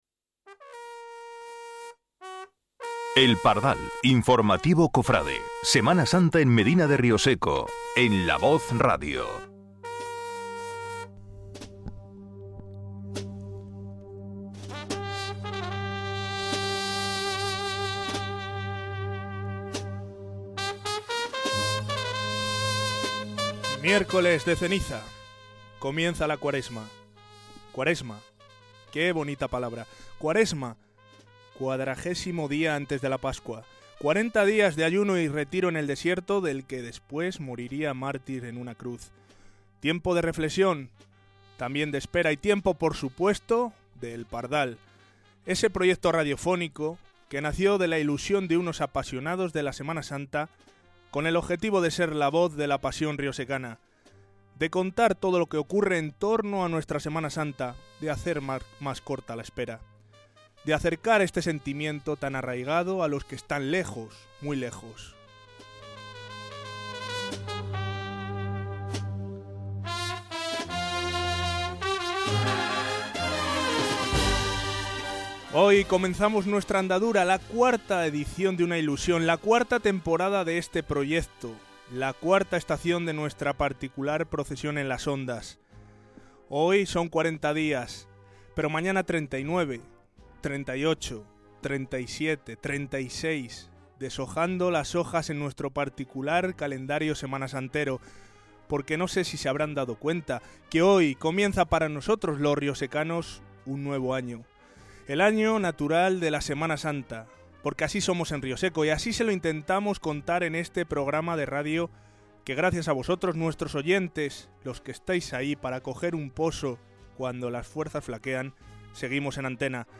La sorpresa vino cuando la banda al completo hizo aparición en la calle de Las Armas e interpretó dos marchas dedicadas a todos los oyentes que pusieron la emoción a estos 90 mnutos de información semanasantera.
Varios músicos aprovecharon los micrófonos de La Voz para felicitar a todos los miembros de la banda de la Clemencia.